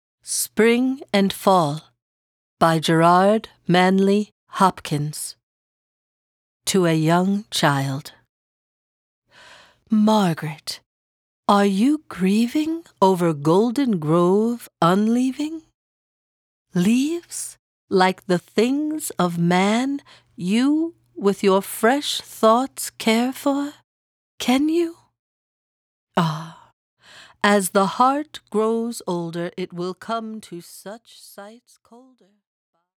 (Narrator)